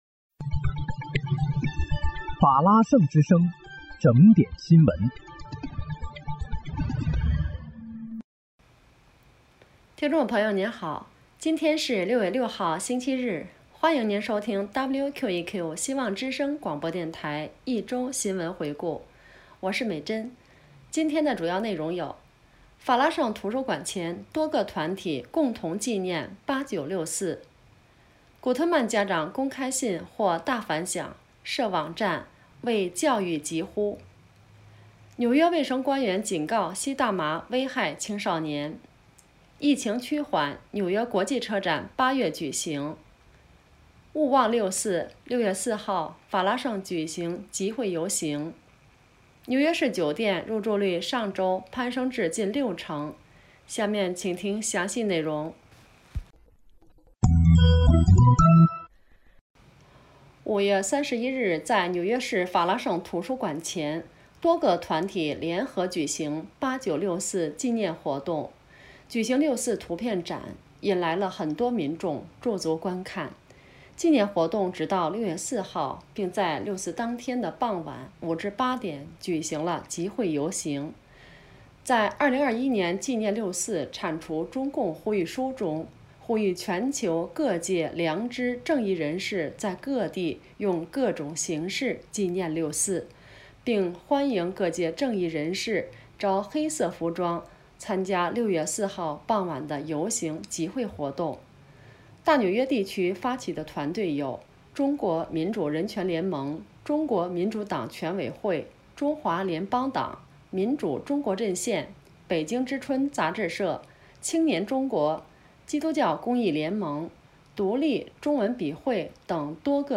6月6日（星期日）一周新闻回顾